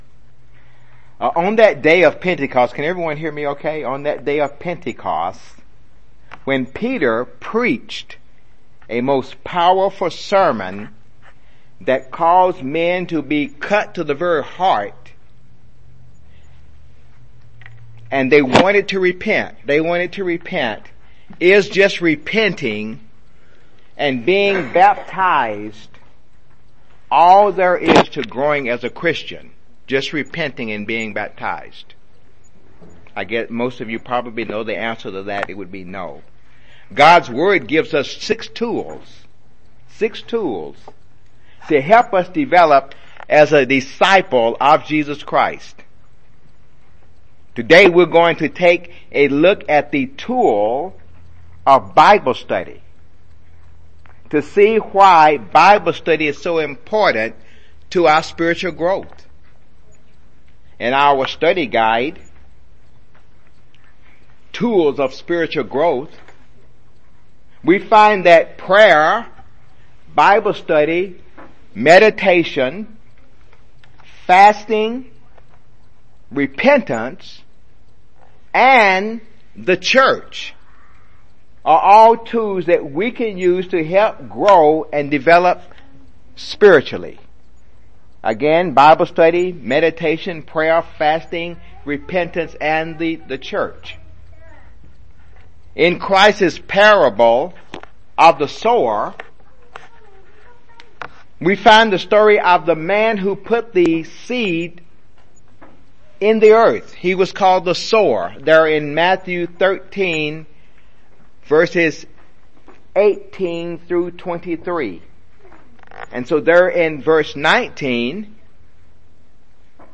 Given in Jackson, TN
UCG Sermon Studying the bible?